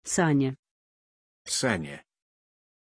Aussprache von Sani
pronunciation-sani-ru.mp3